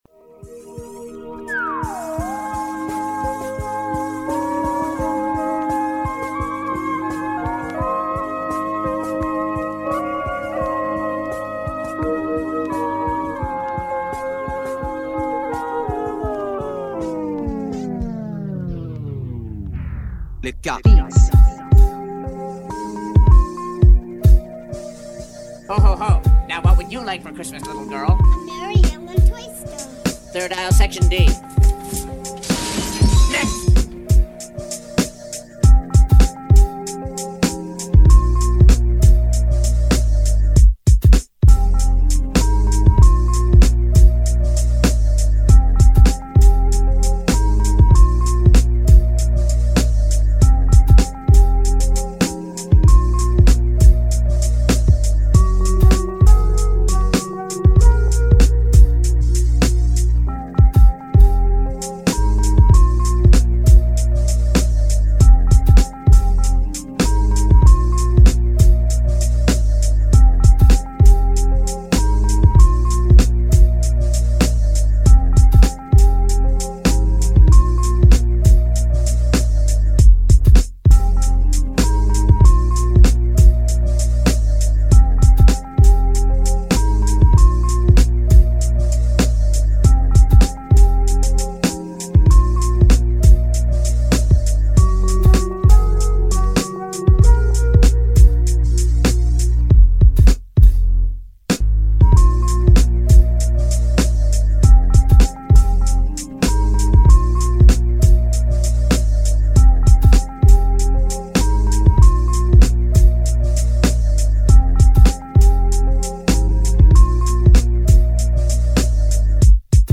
Lo-Fi holiday music